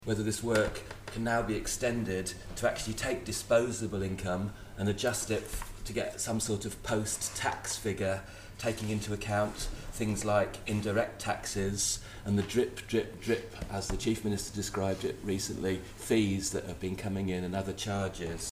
He is questioning if the Cabinet Office can extend the work done for the 2013 Household Income and Expenditure Survey: